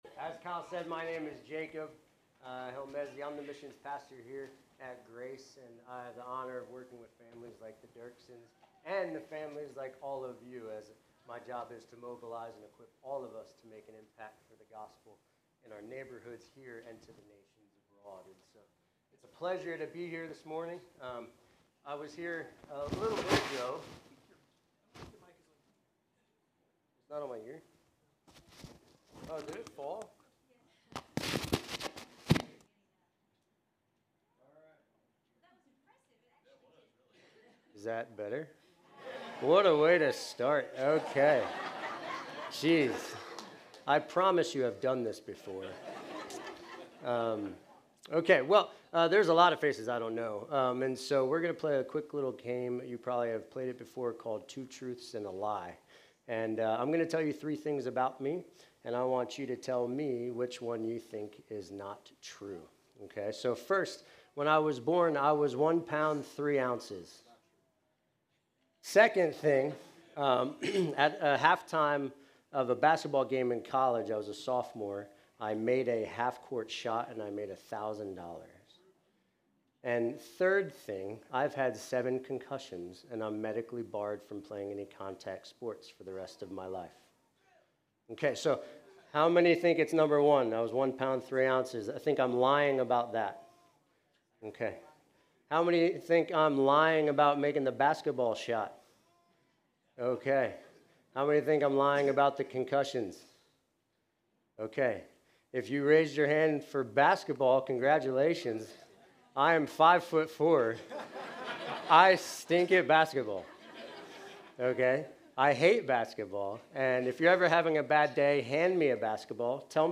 Grace Community Church Dover Campus Sermons 6_8 Dover Campus Jun 08 2025 | 00:28:55 1x 00:00 / 00:28:55 Subscribe Share RSS Feed Share Link Embed